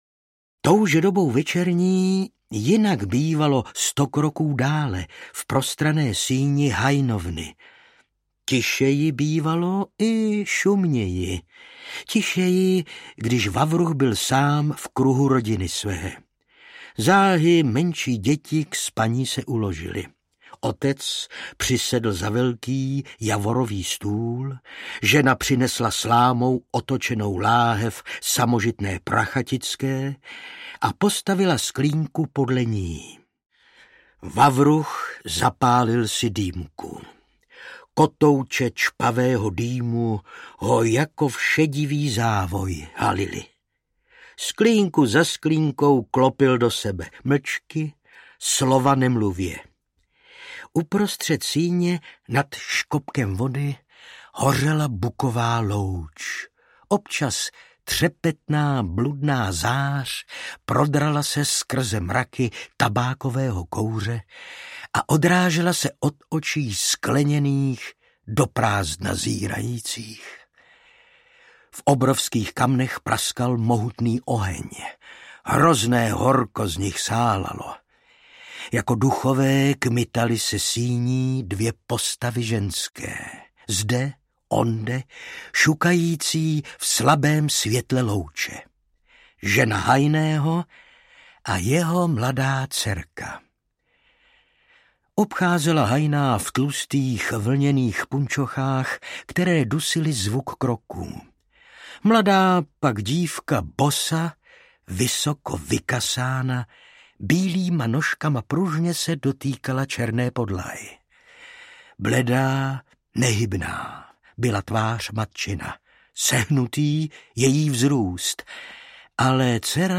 Ze světa lesních samot audiokniha
Ukázka z knihy
Vyrobilo studio Soundguru.